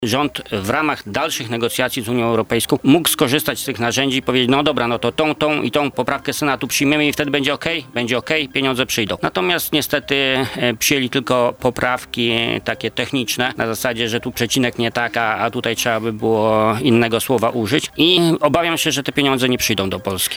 Senator Jacek Bury w Porannej Rozmowie Radia Centrum przyznał, że nie rozumie działań PiSu i miesięcy poświęconych na kompromis.